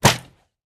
Minecraft Version Minecraft Version latest Latest Release | Latest Snapshot latest / assets / minecraft / sounds / item / crossbow / shoot3.ogg Compare With Compare With Latest Release | Latest Snapshot
shoot3.ogg